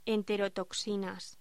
Locución: Enterotoxinas